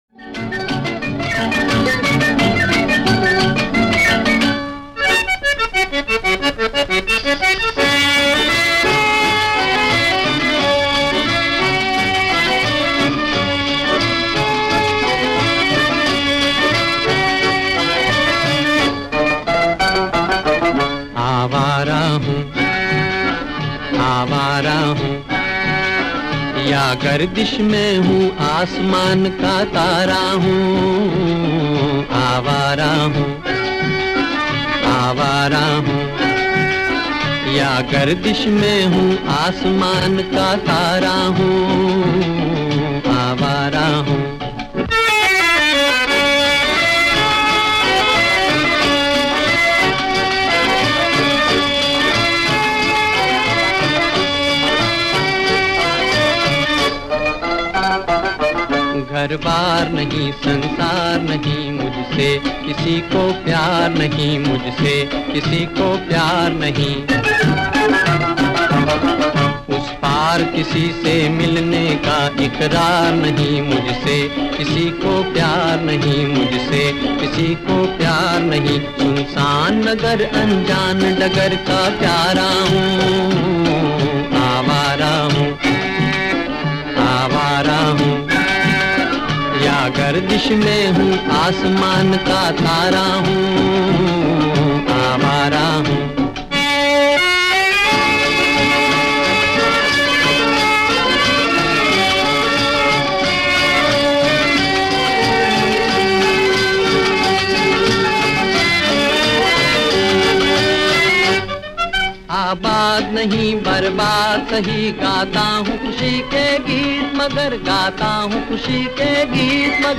全部用原文演唱，风格纯正，韵味浓郁。
原版录音